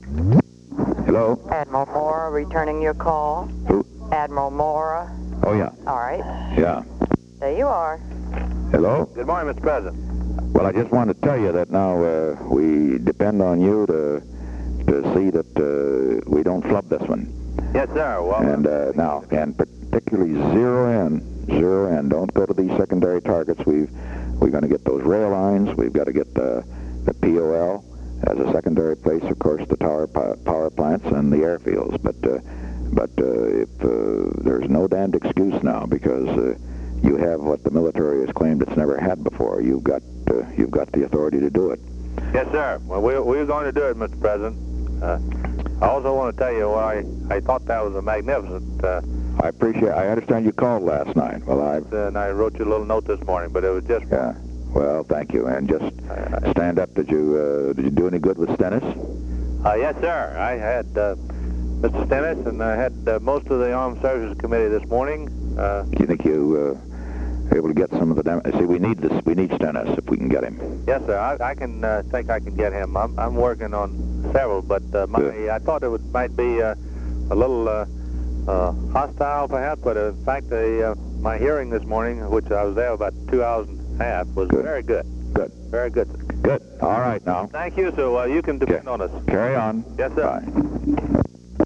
Location: White House Telephone
The President talked with Thomas H. Moorer.